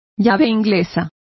Complete with pronunciation of the translation of spanners.